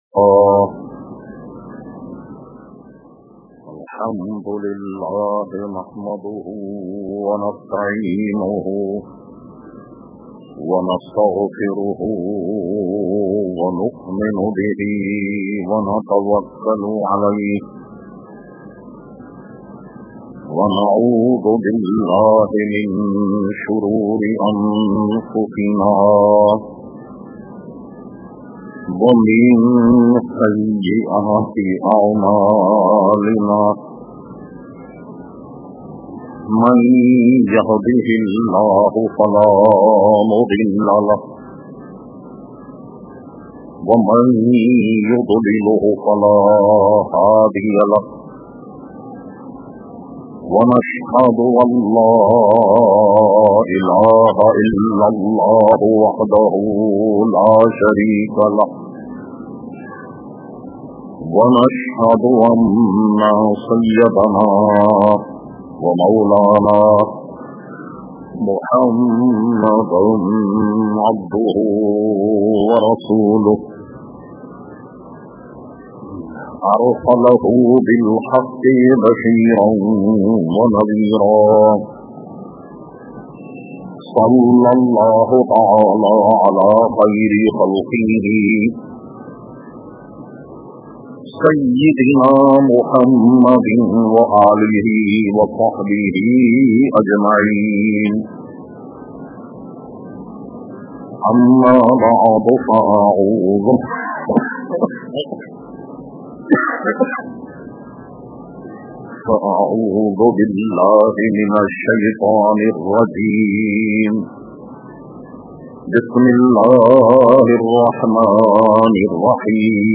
Category : Speeches | Language : Urdu